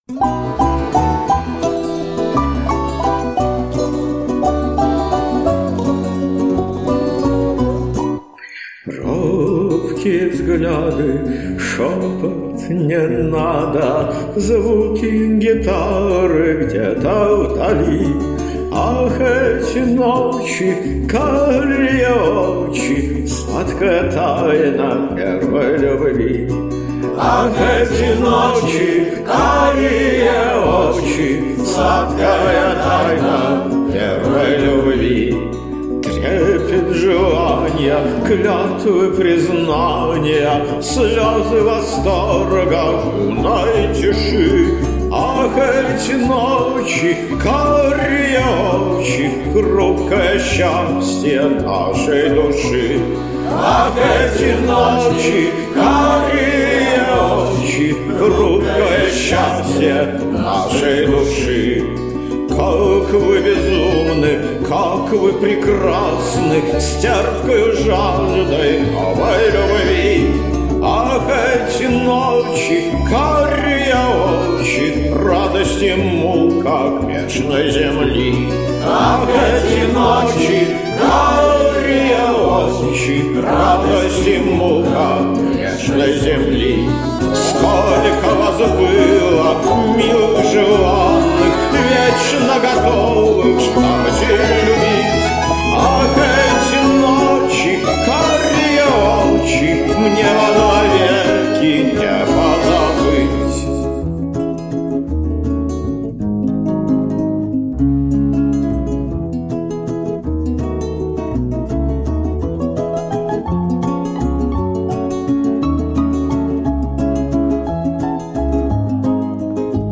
романс.